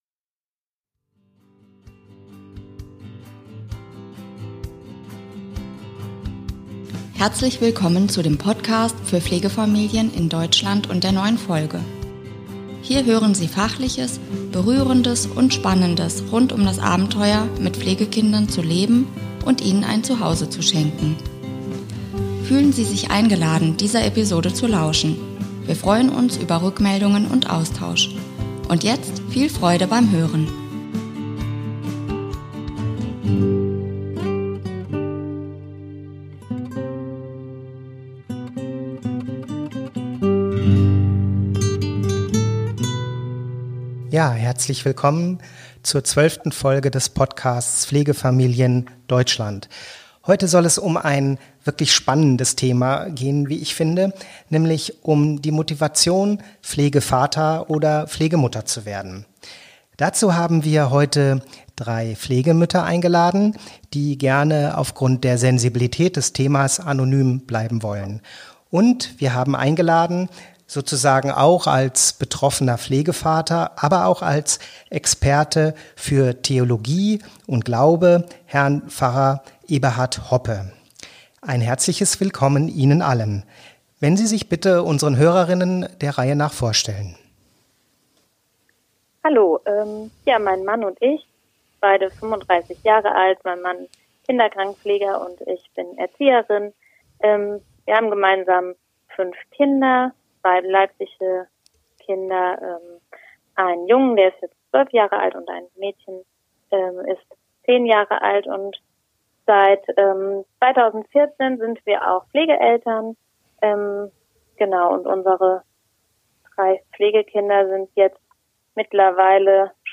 Dazu haben wir drei Pflegemütter (die gerne anonym bleiben möchten)